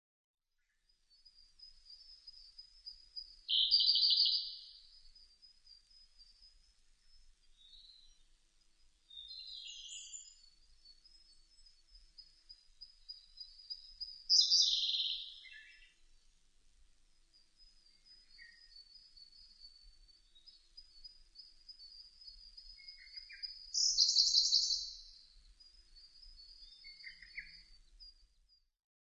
コルリ　Luscunia cyaneツグミ科
片品村菅沼　alt=1750m  HiFi --------------
Mic.: Sound Professionals SP-TFB-2  Binaural Souce
他の自然音：　 ウグイス・ルリビタキ・ホトトギス・ミソサザイ